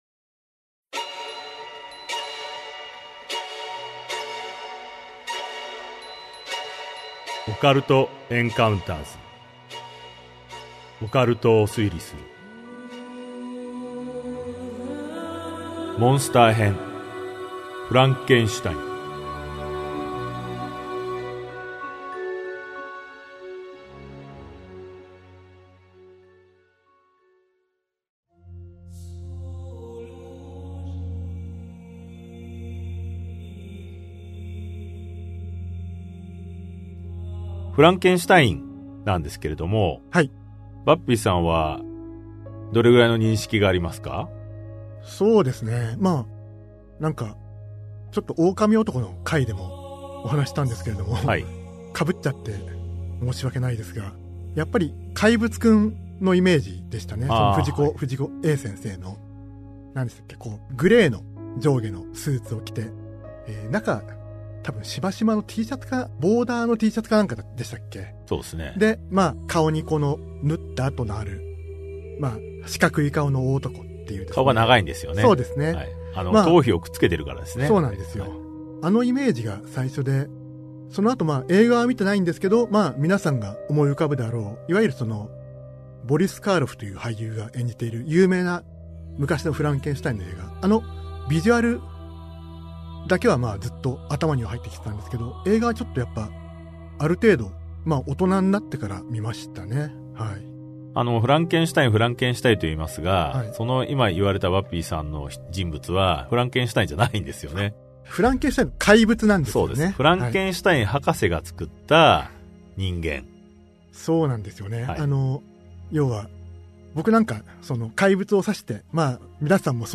[オーディオブック] オカルト・エンカウンターズ オカルトを推理する Vol.14 フランケンシュタイン ゾンビ